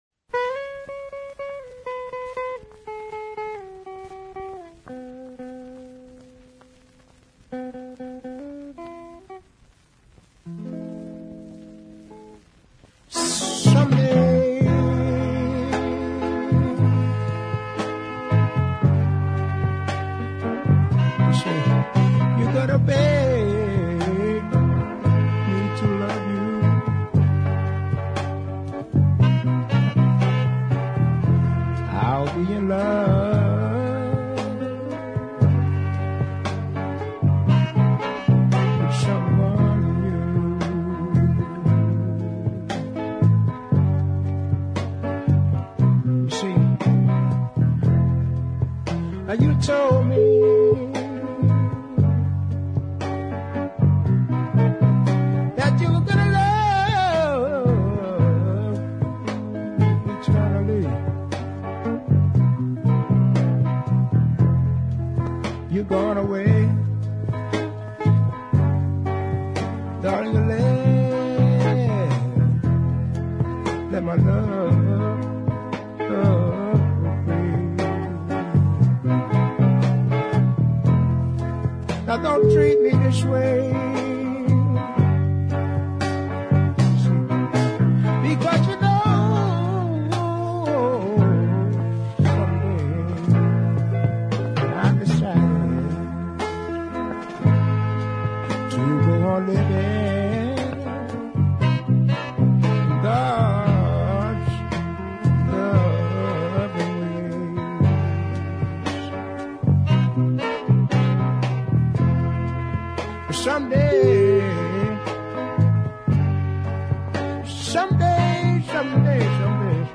There were two ballads amongst the cuts